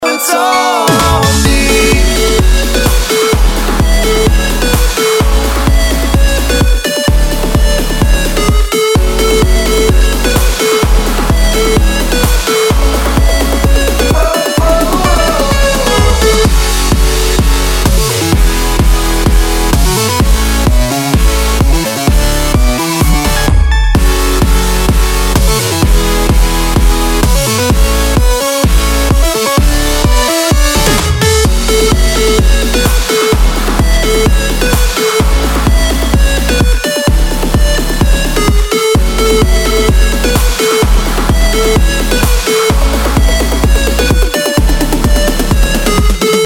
Kategória: Dubstep
Minőség: 320 kbps 44.1 kHz Stereo